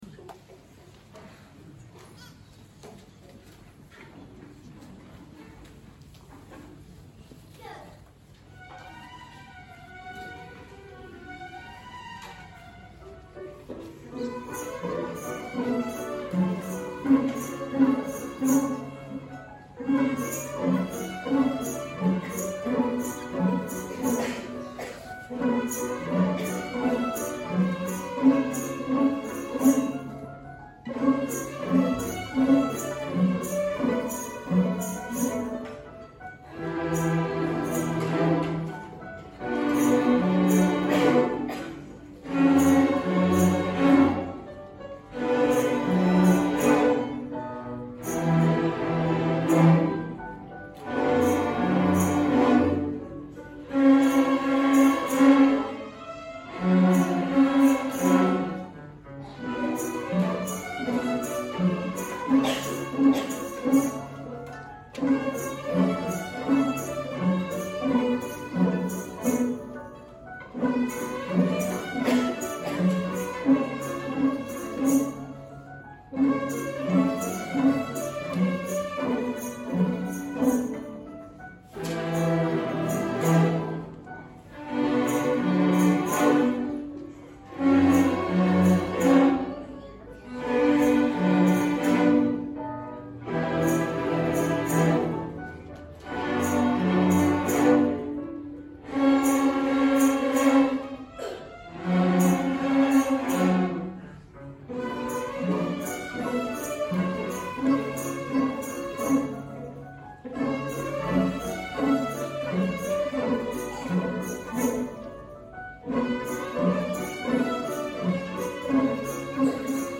Mazel Tov | Larch String Orchestra